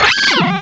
cry_not_weavile.aif